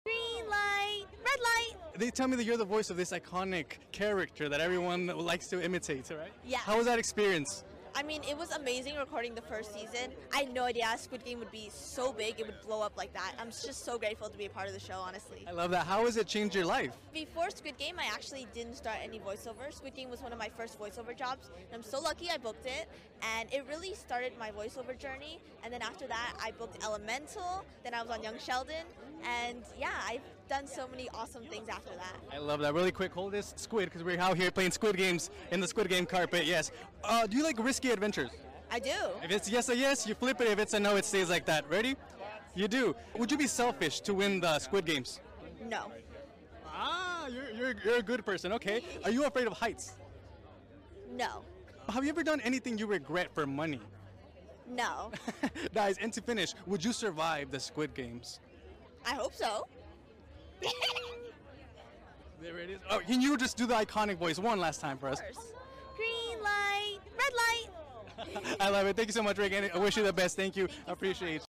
Interview at Squid Game Premiere